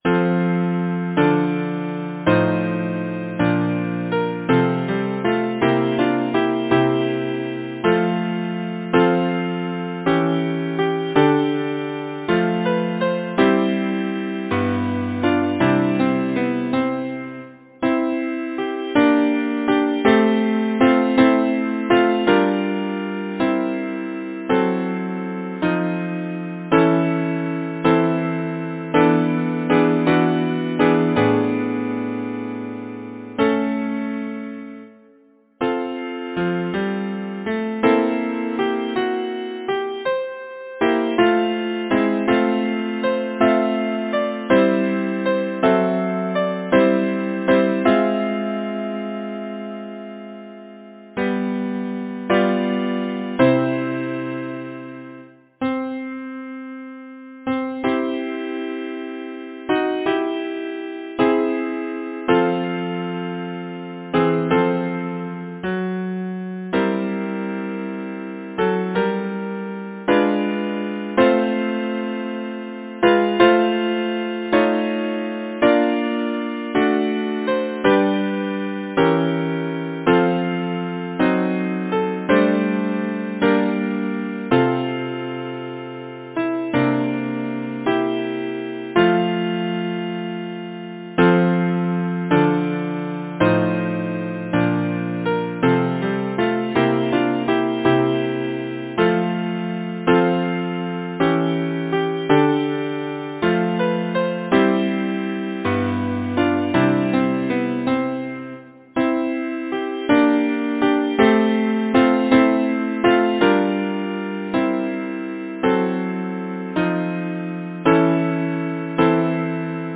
Title: Now the wearied sun declining Composer: Richard Francis Lloyd Lyricist: Number of voices: 4vv Voicing: SATB Genre: Secular, Partsong
Language: English Instruments: A cappella